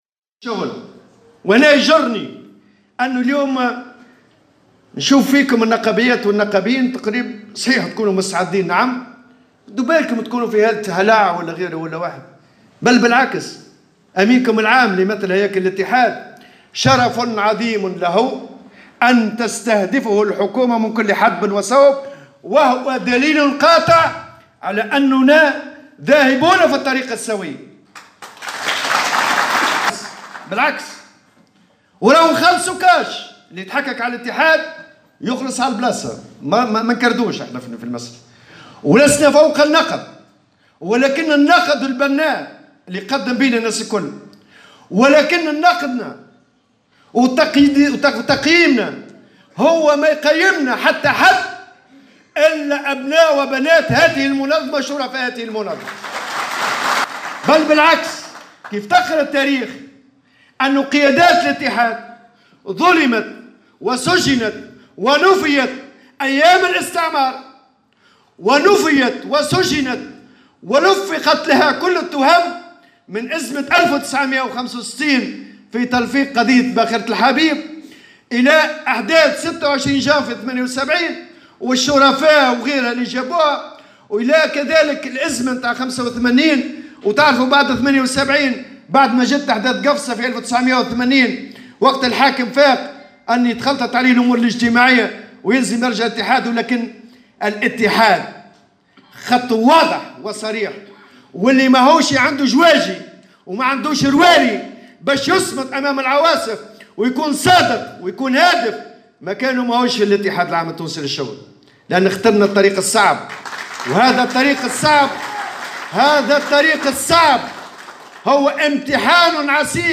قال الأمين العام للإتحاد العام التونسي للشغل في كلمة ألقاها أمام النقابيين في إفتتاح الندوة الوطنية الخاصة بالمفاوضات الإجتماعية القادمة إن له الشرف الكبير أن تستهدفه الحكومة.